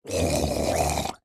Minecraft Version Minecraft Version snapshot Latest Release | Latest Snapshot snapshot / assets / minecraft / sounds / mob / drowned / idle2.ogg Compare With Compare With Latest Release | Latest Snapshot